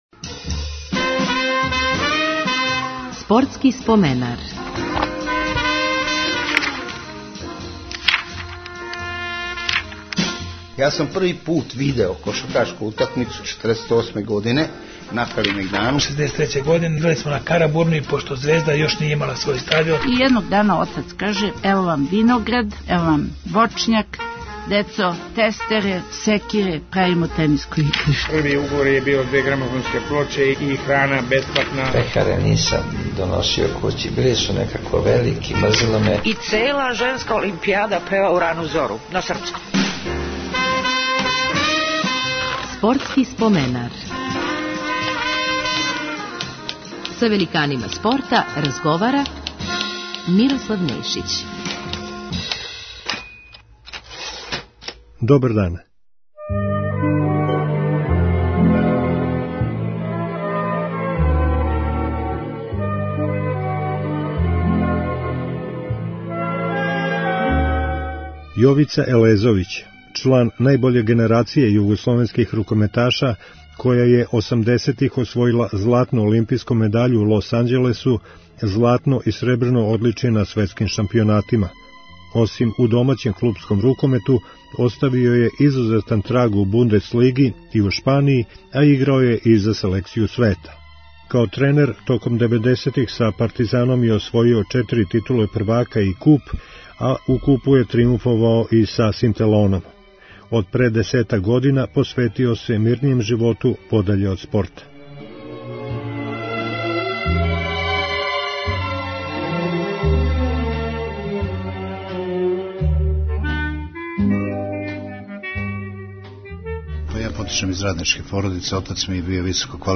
Гост 399. емисије је рукометаш и тренер Јовица Елезовић.